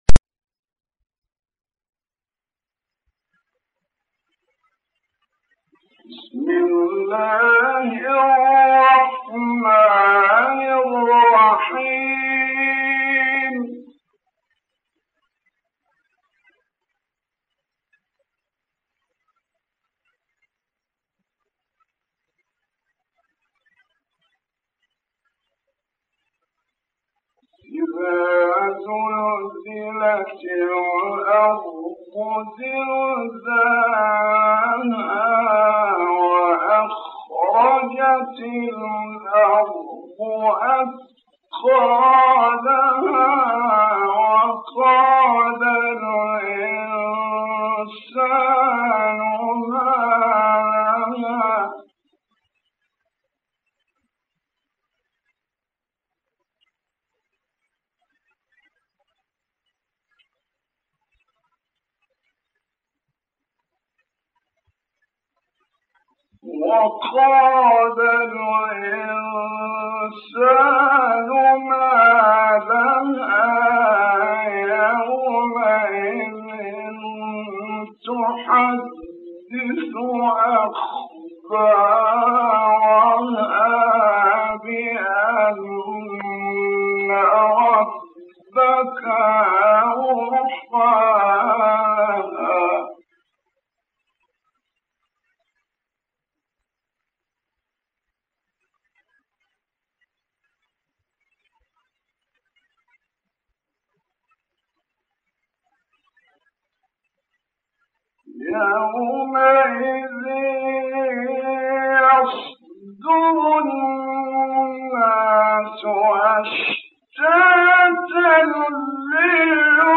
۞ تسجيل نادر جداً لما تيسر من قصار السور (1) - للقارئ الكبير : محمد رفعت ۞